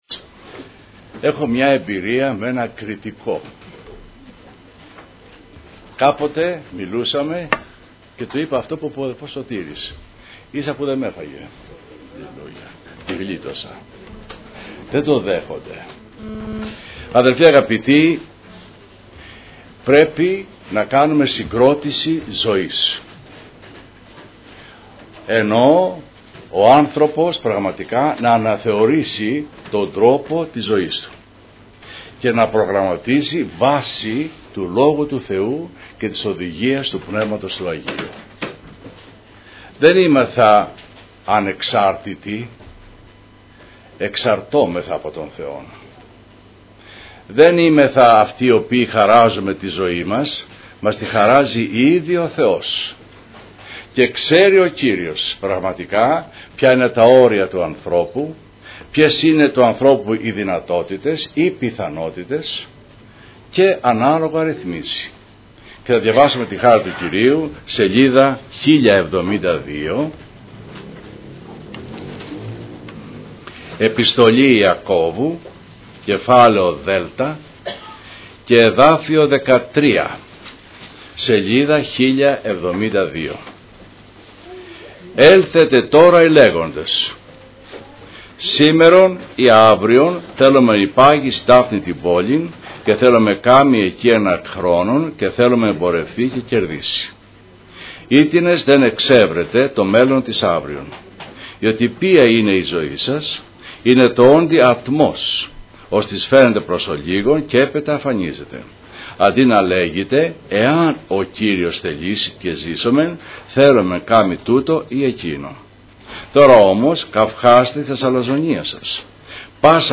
Διάφοροι Ομιλητές Λεπτομέρειες Σειρά: Κηρύγματα Ημερομηνία